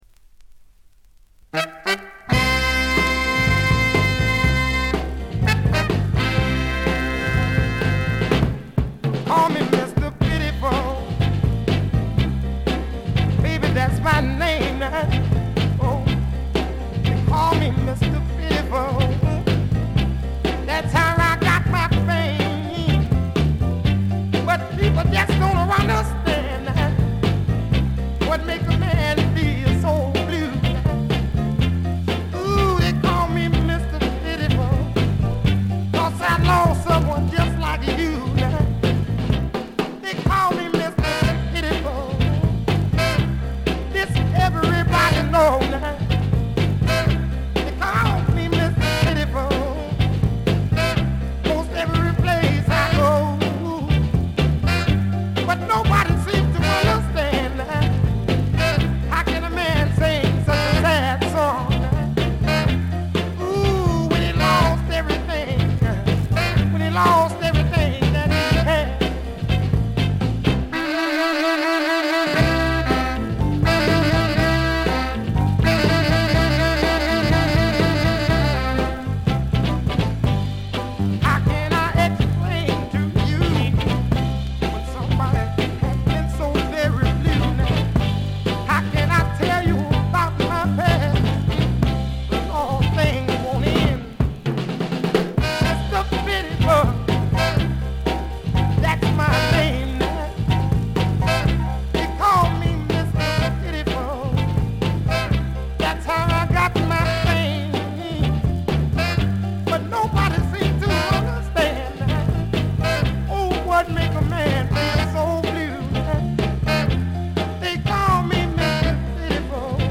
バックグラウンドノイズ（A2序盤が特に目立つ）、チリプチ。
モノラル盤。
試聴曲は現品からの取り込み音源です。